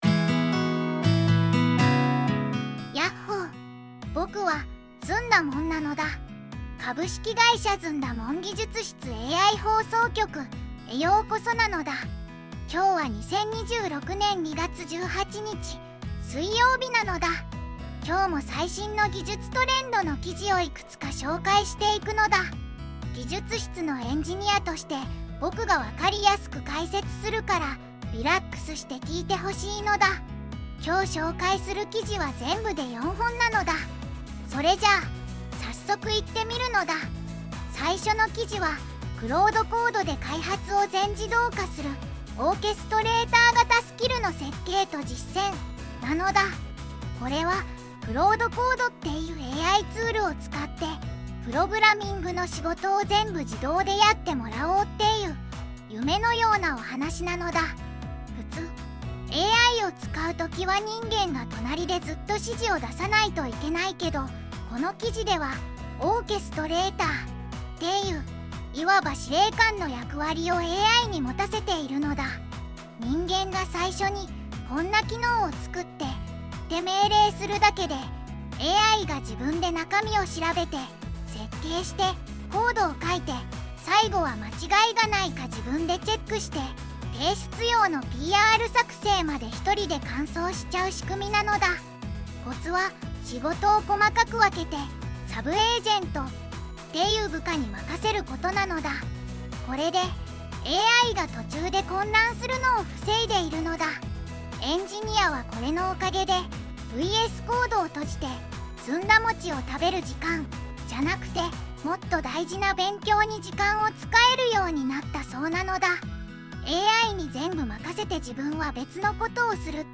AIやテクノロジーのトレンドを届けるPodcast。
ずんだもん